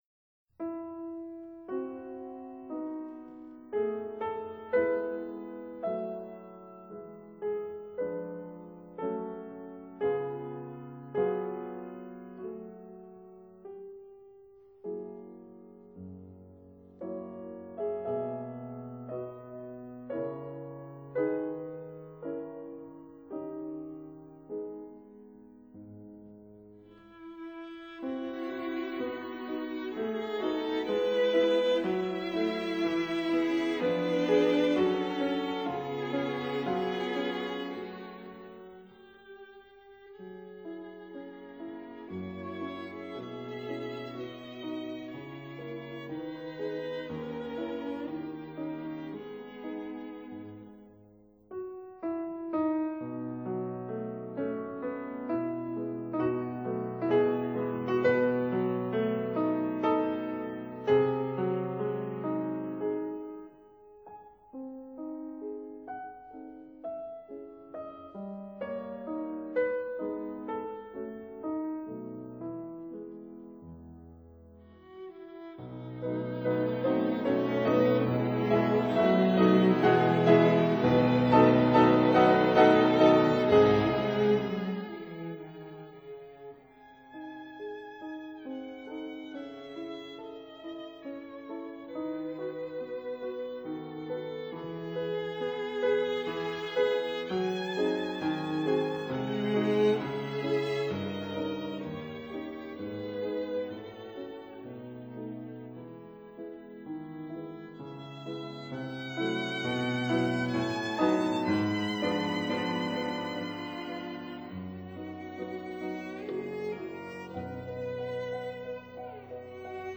Andante con moto
piano
violin
cello